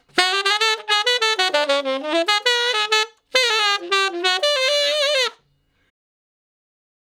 066 Ten Sax Straight (D) 35.wav